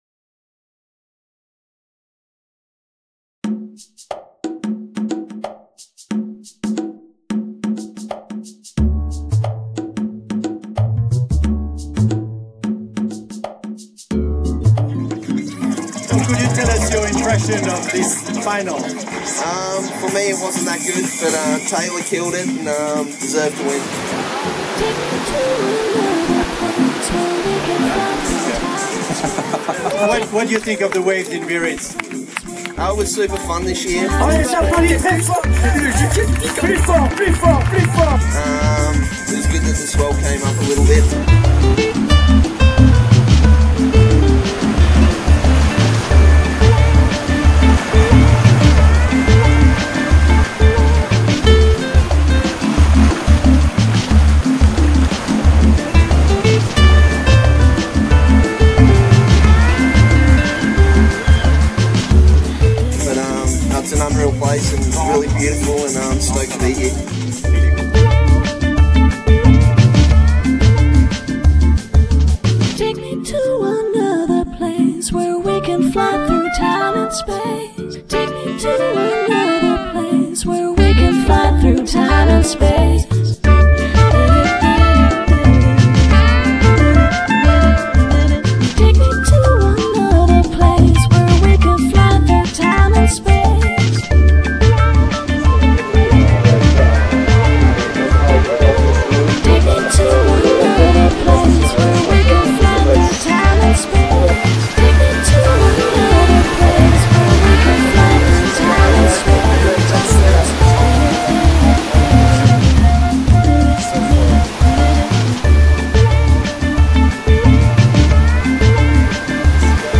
No skateboarding, no cones and no sound of wheels sliding on the asphalt.